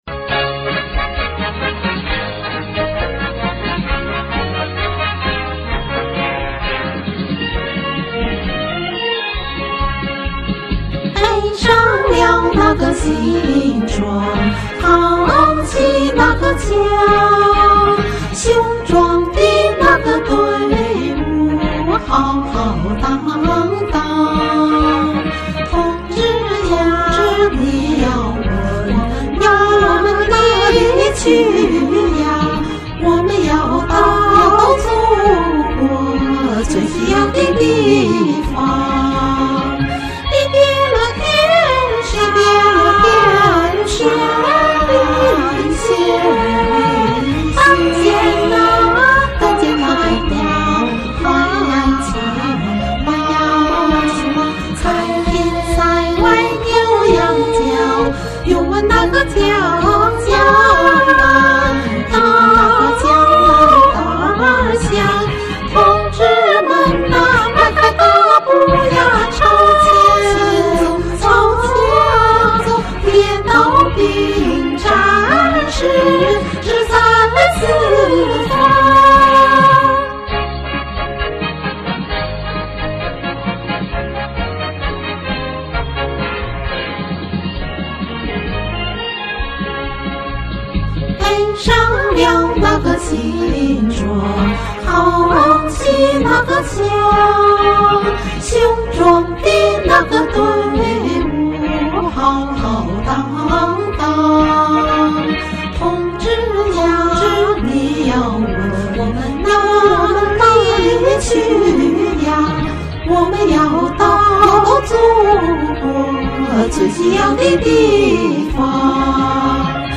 現在先在高山操練一番，在網上找到一版兩個聲部的譜子，試唱一版。
雄壯豪邁抒情優美
是一首廣為流傳的軍歌